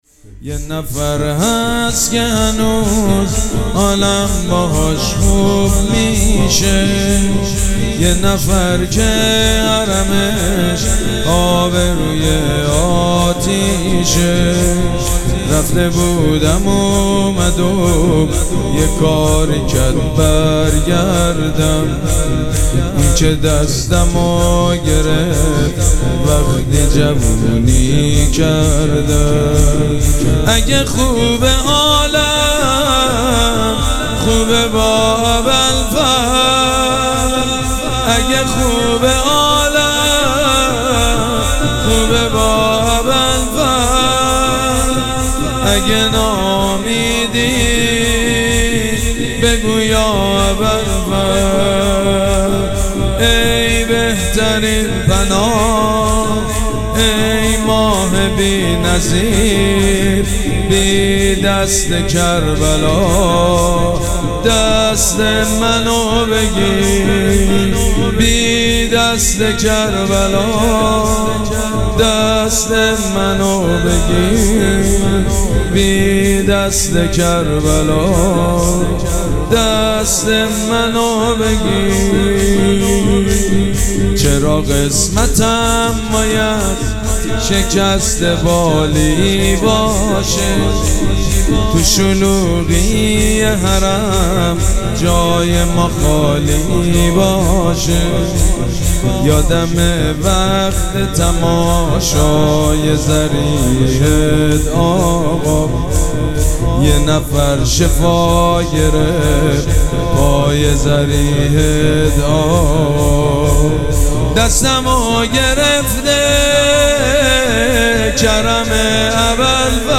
شب پنجم مراسم عزاداری اربعین حسینی ۱۴۴۷
شور
مداح
حاج سید مجید بنی فاطمه